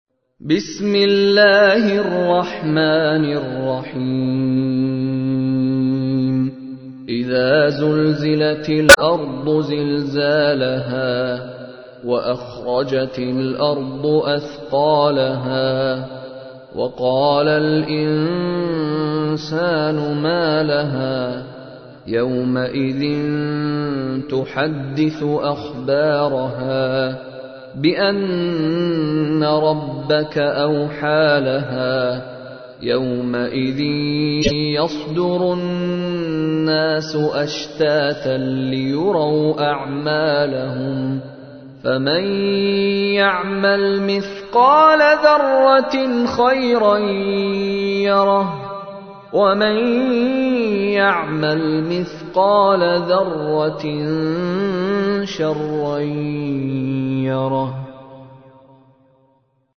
تحميل : 99. سورة الزلزلة / القارئ مشاري راشد العفاسي / القرآن الكريم / موقع يا حسين